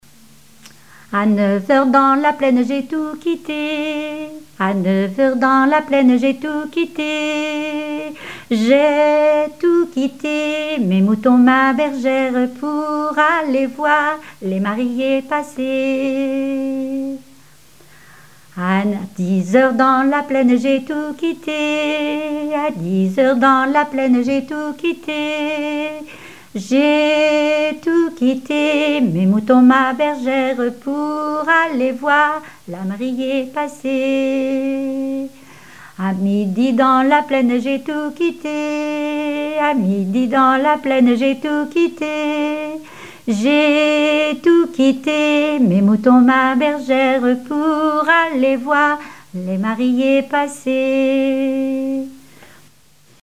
gestuel : à marcher
Genre énumérative
chansons populaires et traditionnelles